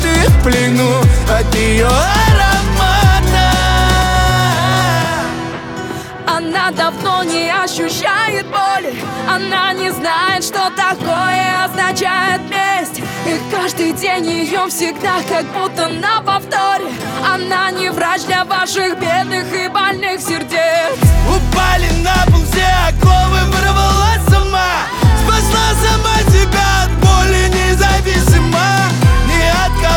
Pop Alternative Indie Pop